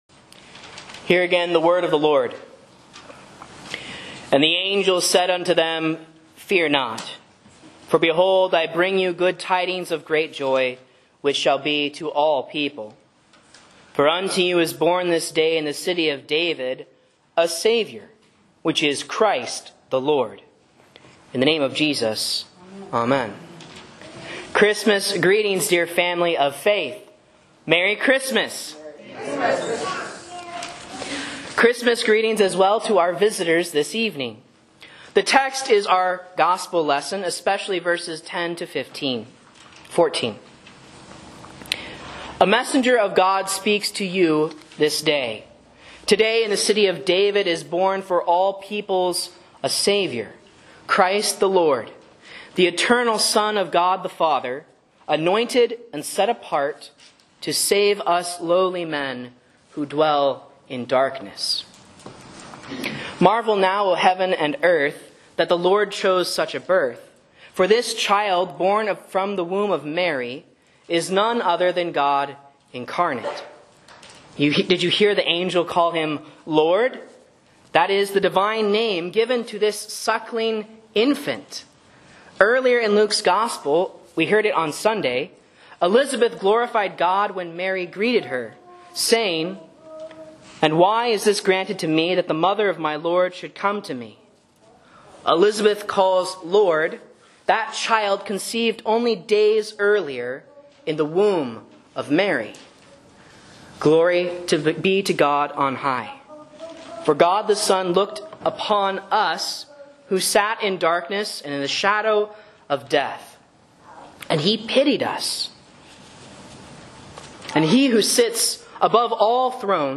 Sermons and Lessons from Faith Lutheran Church, Rogue River, OR
A Sermon on Luke 2.10-14 & Isa 9.2